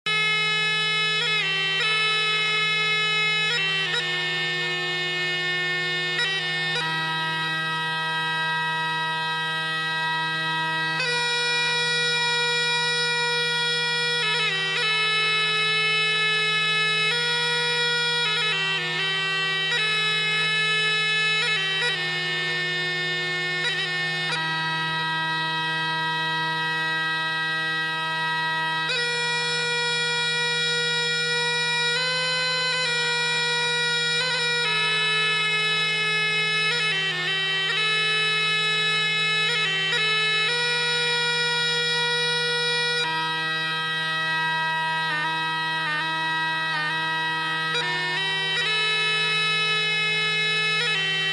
Дуда
duda.mp3